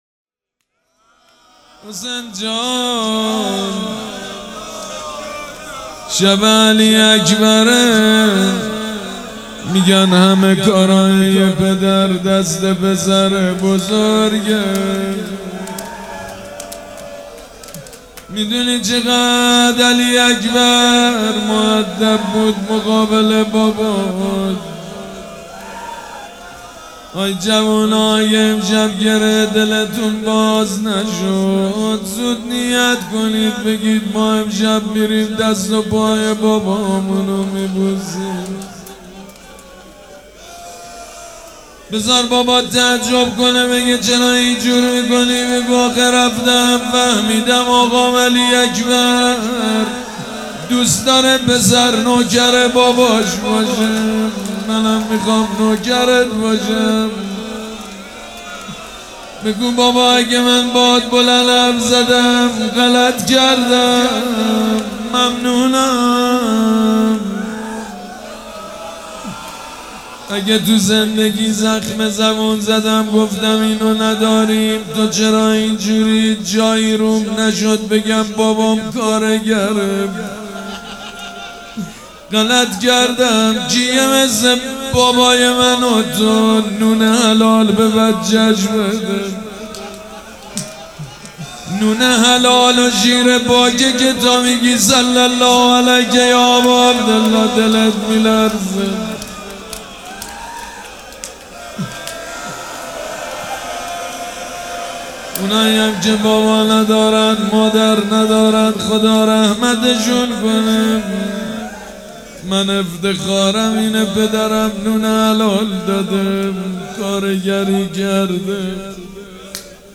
روضه
مداح
مراسم عزاداری شب هشتم